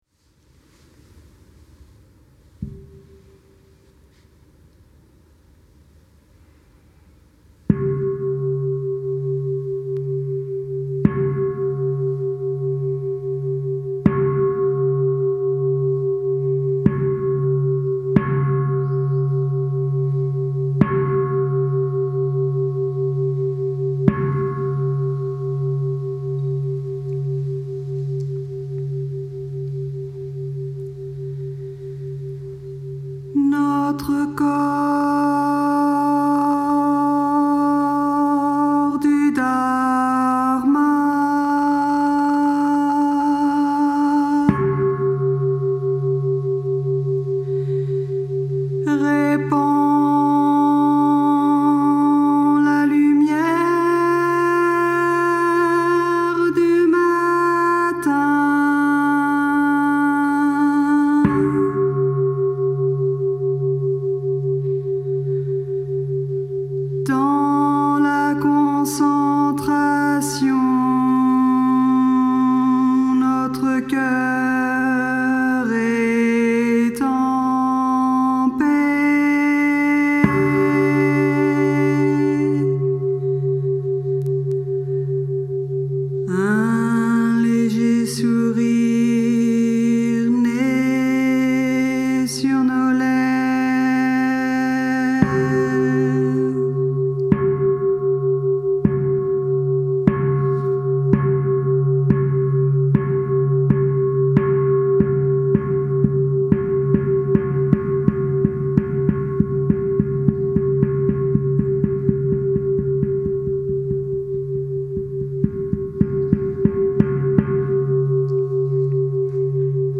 Chants / Chant du matin